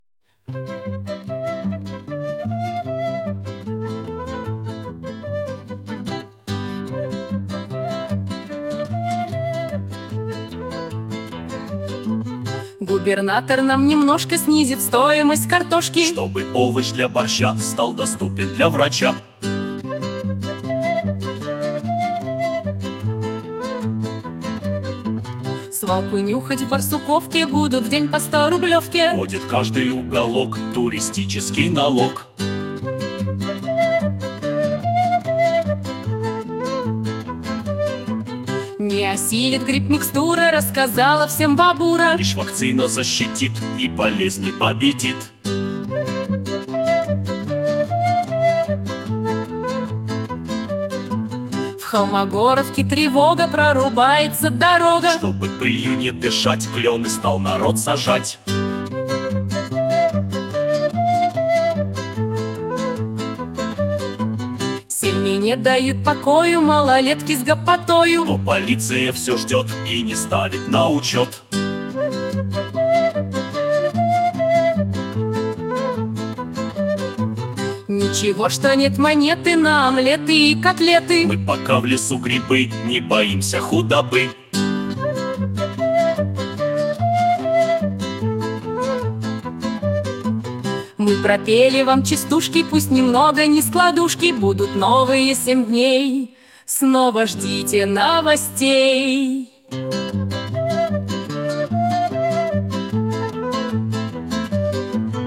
О главных событиях — в виде комических куплетов
Частушки на новость (злобу) дня 02.11
Частушки_Клопс_02.11.mp3